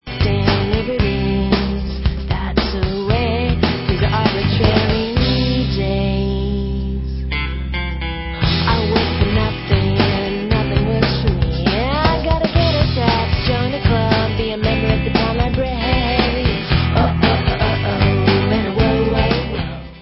sledovat novinky v oddělení Alternativní hudba